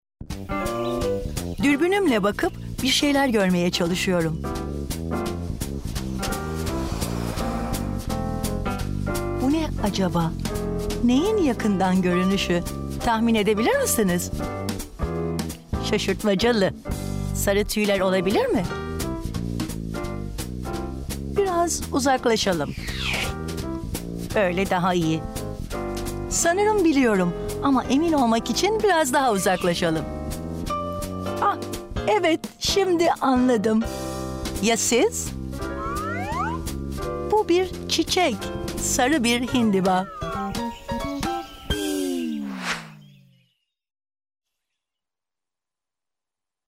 Sprechprobe: eLearning (Muttersprache):
I do speak Turkish with an Istanbul accent (proper accent such as BBC or Oxford accent in the UK.